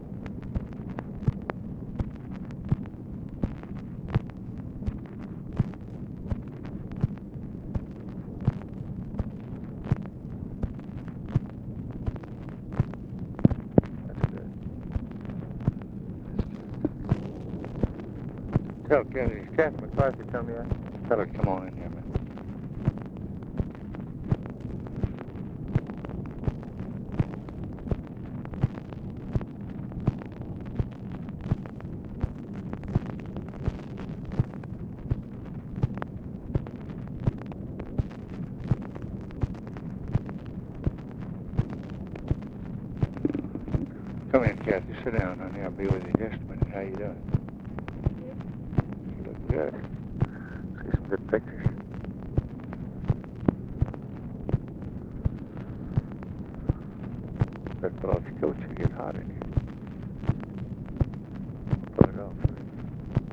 OFFICE CONVERSATION, February 24, 1964
Secret White House Tapes